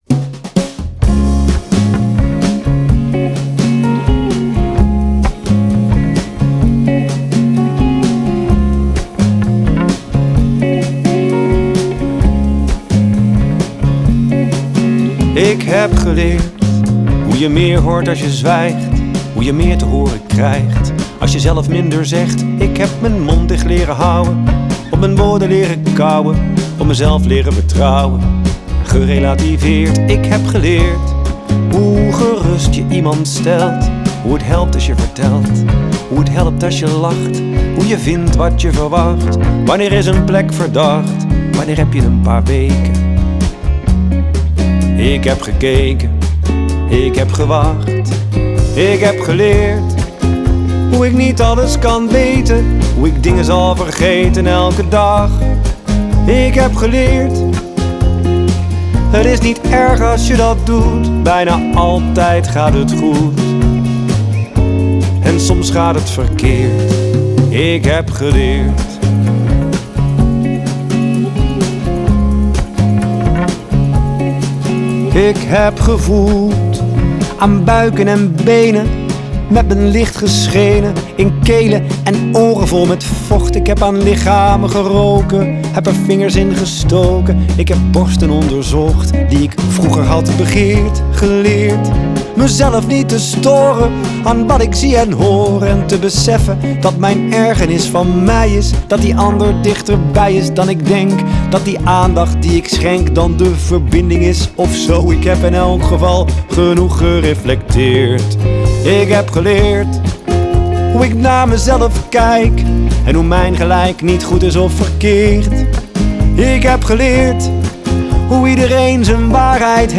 Zang, gitaar
Gitaar
Bas, toetsen
Slagwerk